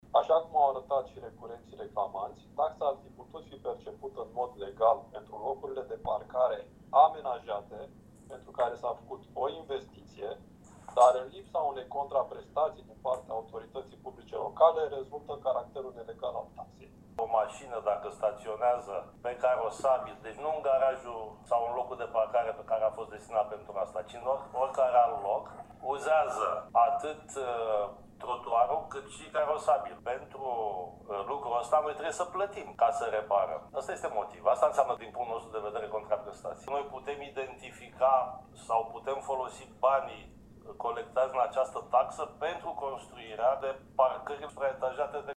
Consilierul local USR Cătălin Iacob i-a cerut primarul Vergil Chițac să explice care va fi contraprestația municipalității în schimbul sumei: